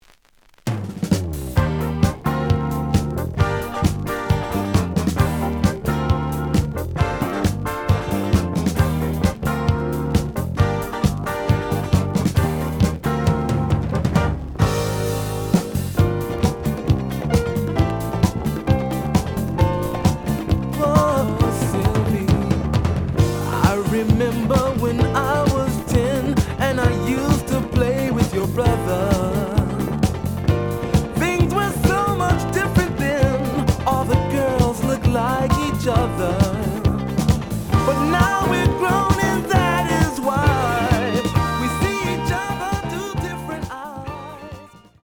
The audio sample is recorded from the actual item.
●Genre: Funk, 70's Funk
Slight edge warp. But doesn't affect playing. Plays good.)